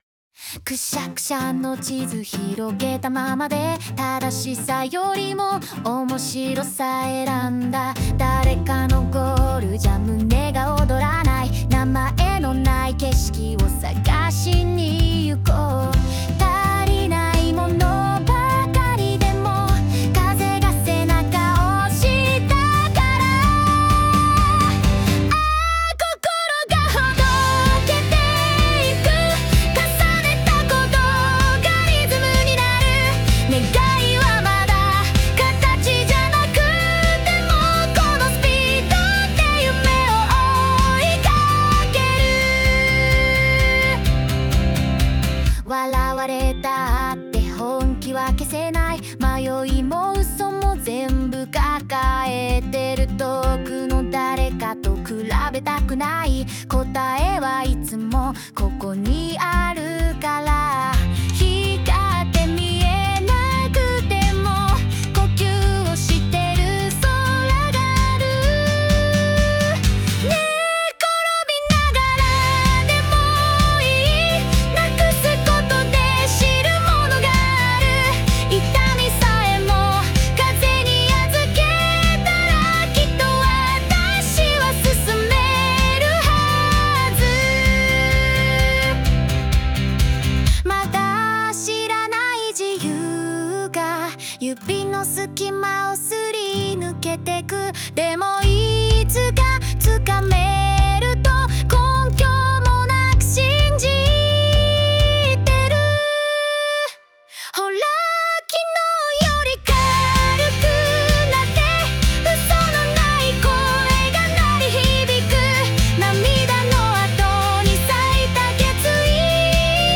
邦楽女性ボーカル著作権フリーBGM ボーカル
著作権フリーオリジナルBGMです。
女性ボーカル（邦楽・日本語）曲です。
元気で前向きなメロディの裏側に、誰もが心のどこかに抱えている弱さや迷いがそっと描かれています。